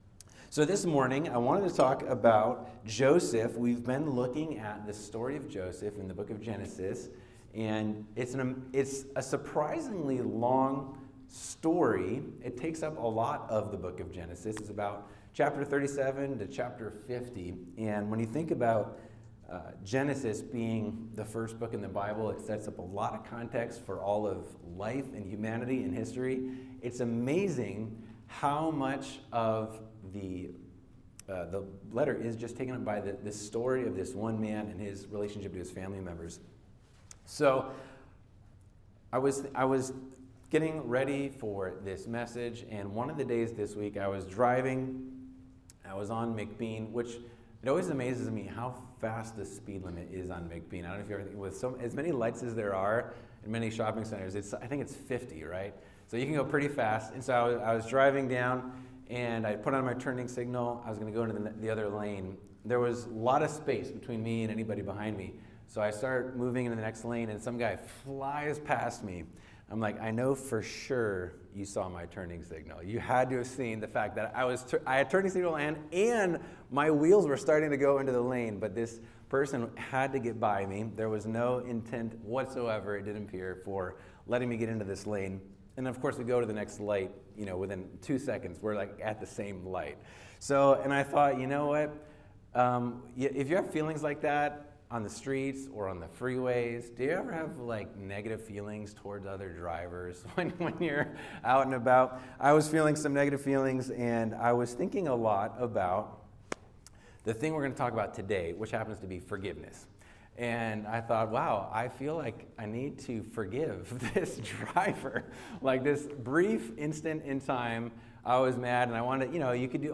Listen to previous Sunday messages from Valley Lights Church.